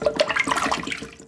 Toilet Water Splash Sound
household
Toilet Water Splash